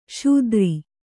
♪ śudri